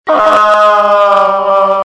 zombie_die_3c.wav